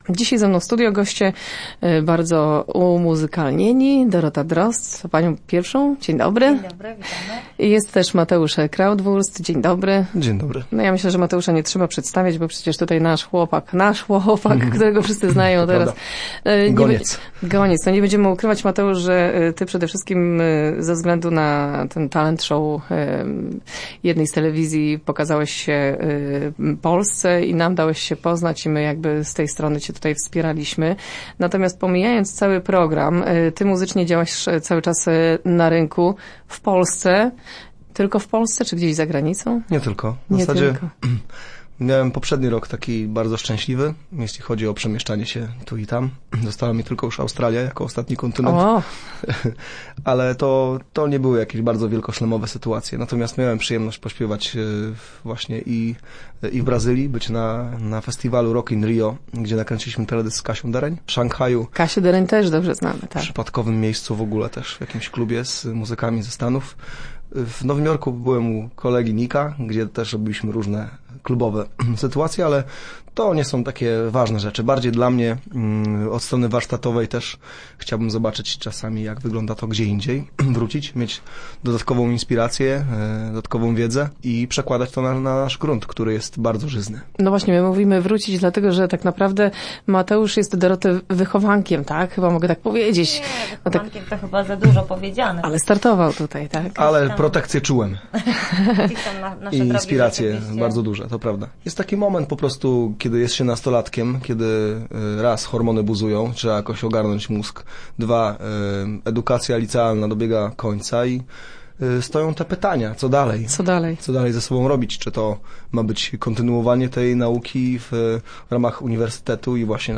radio, głogów, głogowski, Lubin, lubiński, miedz, miedziowe, portal regionu, elka